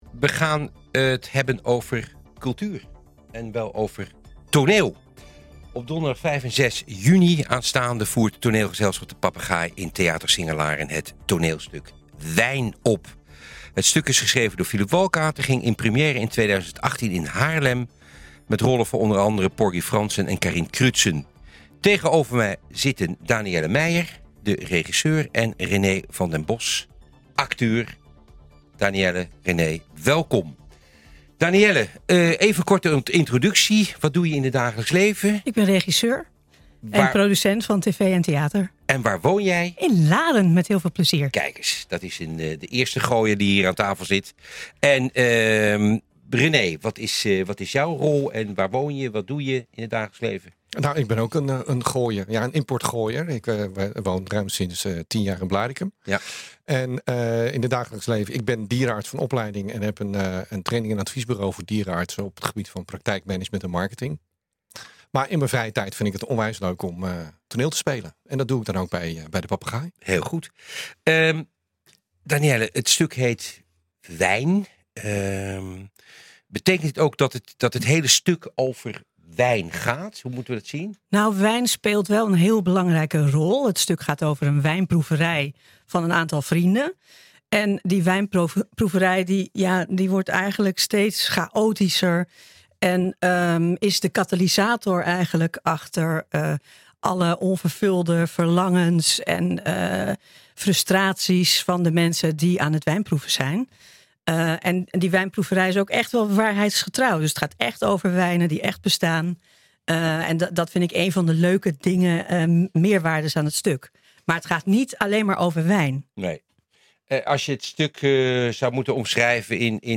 We gaan het over cultuur hebben en wel over het toneel! Op donderdag 5 en vrijdag 6 juni aanstaande voert Toneelgezelschap De Papegaai in theater Singer Laren het toneelstuk Wijn op. Het stuk is geschreven door Philip Walkate, ging in première in 2018 in Haarlem met rollen voor onder andere Porgy Franssen en Carine Crutzen.